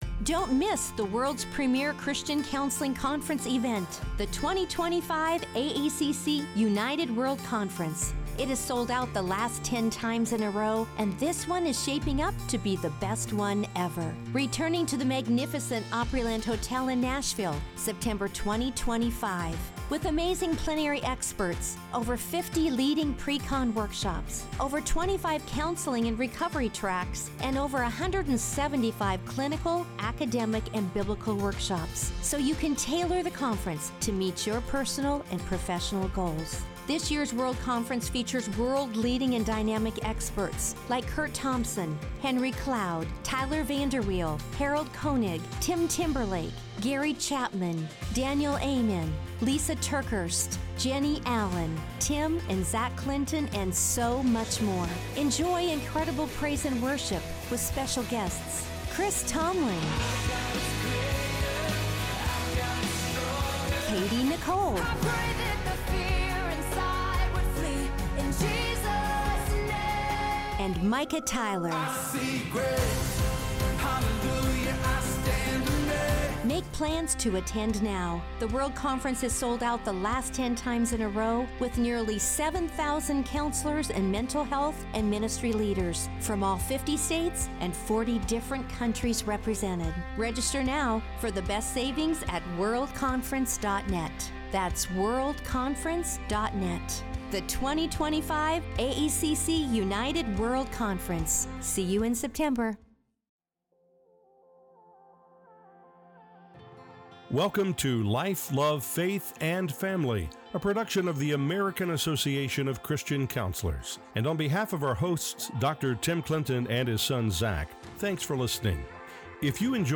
joined on stage at the 2025 Ignite Men’s Impact Weekend by acclaimed college football quarterback Riley Leonard.